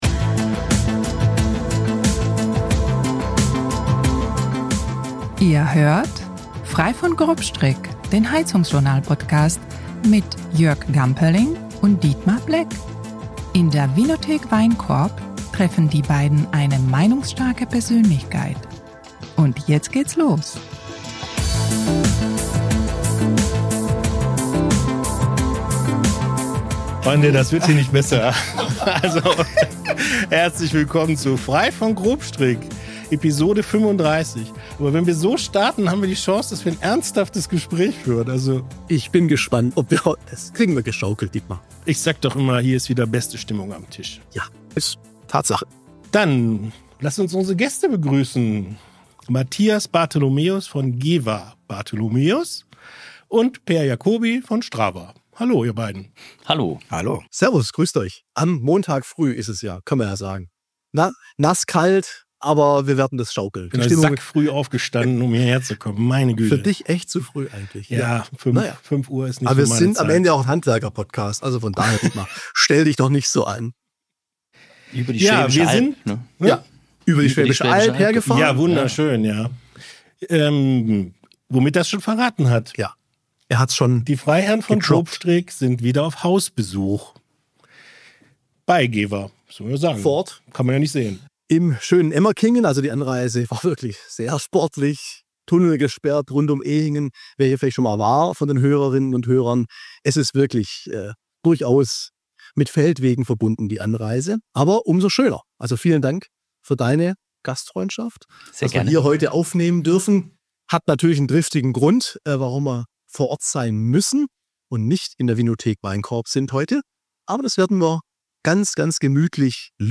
Zwei Gesprächspartner aus zwei Unternehmen, das ist eine Premiere bei „Frei von Grobstrick“. geba und strawa präsentieren mit „SAM 365“ einen völlig neuen Ansatz der Versorgung einzelner Wohneinheiten in Mehrgeschoss-Wohnbauten mit Wärme, Trinkwasser und Außenluft. Die Apartment-Wärmepumpe nutzt die Abluftwärme für Heizung und Warmwasserbereitung und das im ganzen Jahr – daher auch der Zusatz „365“.
Locker bis launig, meinungsstark und informativ, das ist Frei von Grobstrick, der HeizungsJournal- Podcast.